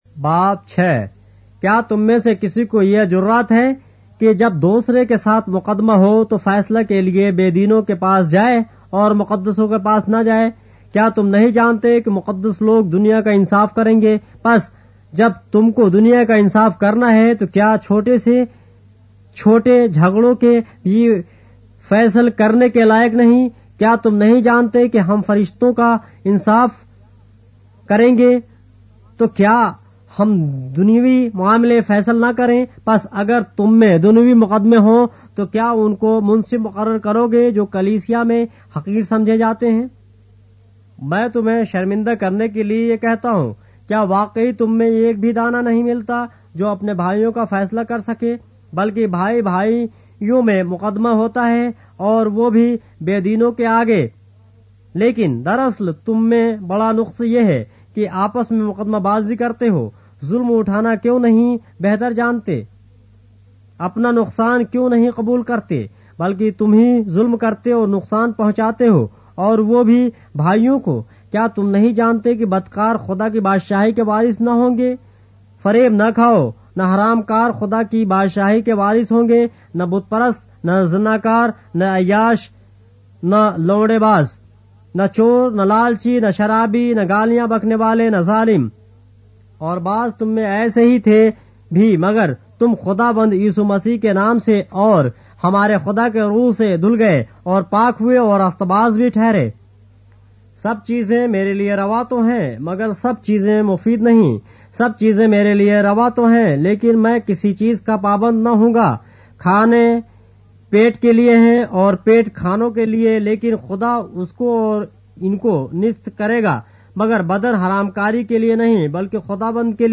اردو بائبل کے باب - آڈیو روایت کے ساتھ - 1 Corinthians, chapter 6 of the Holy Bible in Urdu